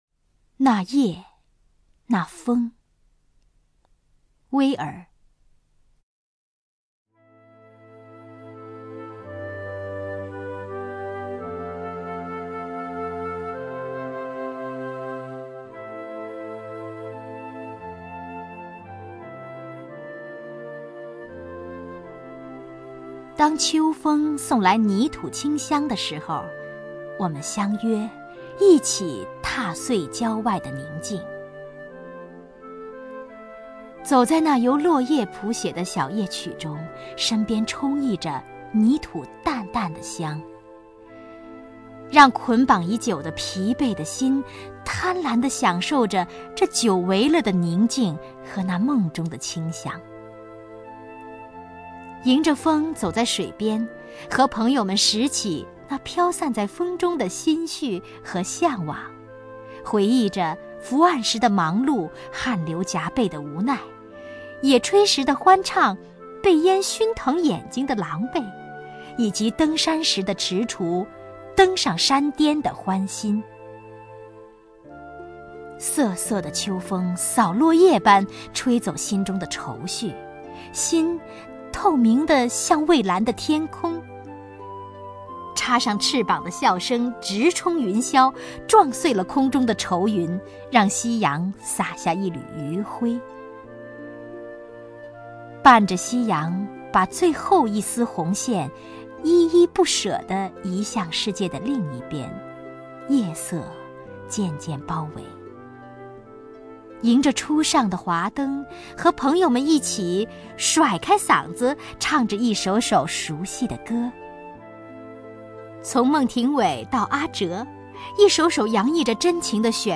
首页 视听 名家朗诵欣赏 王雪纯
王雪纯朗诵：《淡淡一点的……》(微儿)